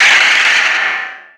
Cri de Méga-Branette dans Pokémon X et Y.
Cri_0354_Méga_XY.ogg